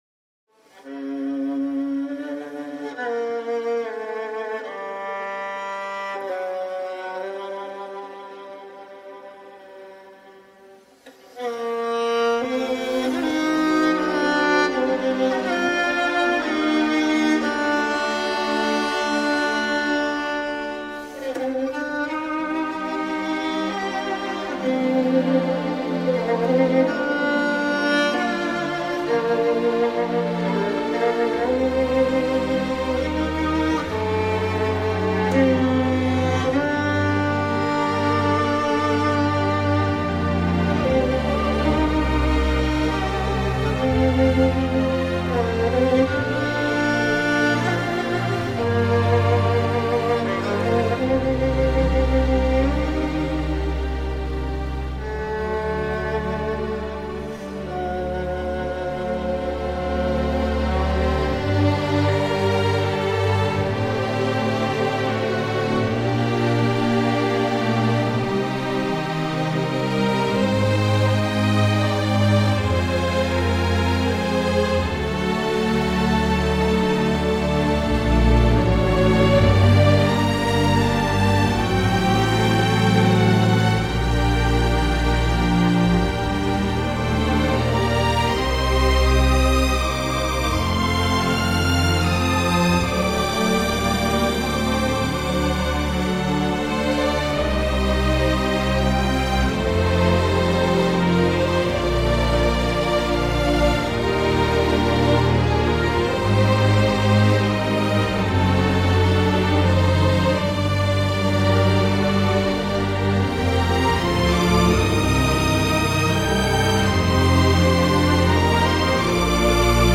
Il tente aussi le romantisme.